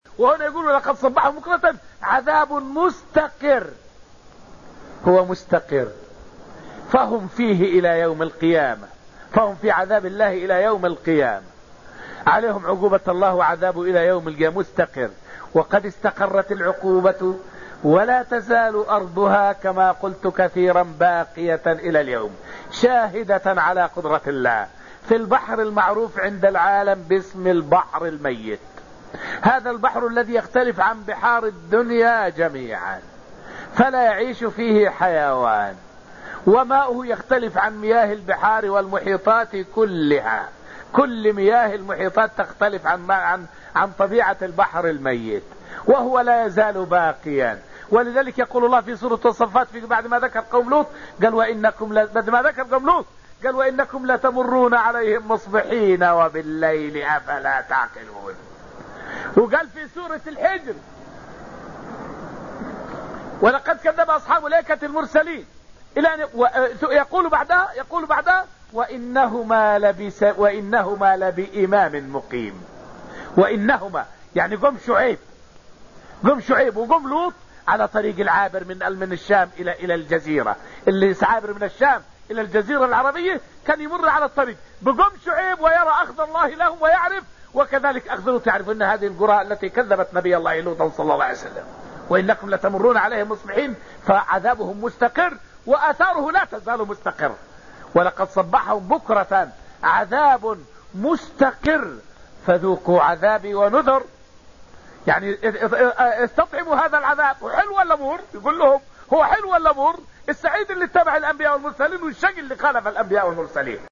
فائدة من الدرس السابع من دروس تفسير سورة القمر والتي ألقيت في المسجد النبوي الشريف حول آثار إهلاك الله لقوم لوط ما زالت باقية.